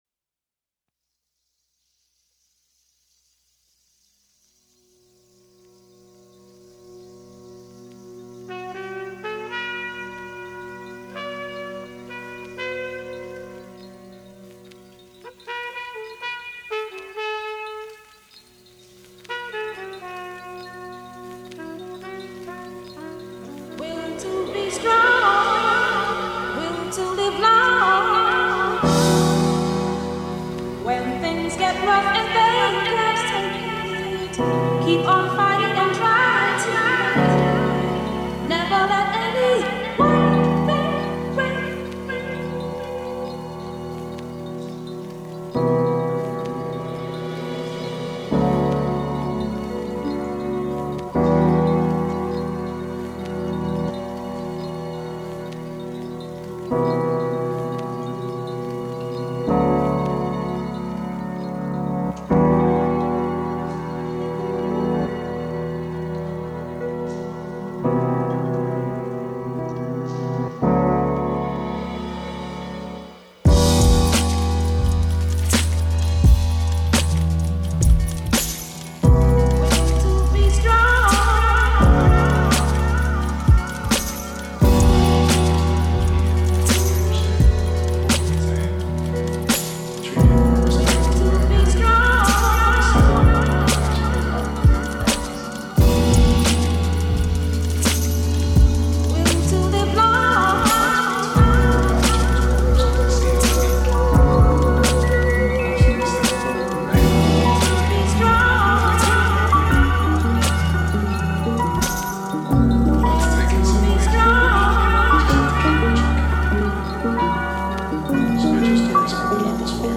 This is a who’s who of Tech Step classics.